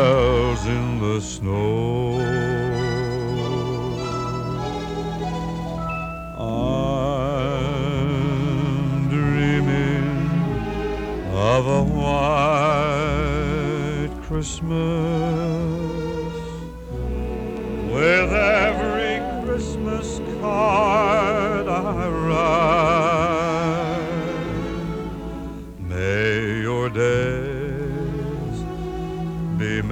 • Holiday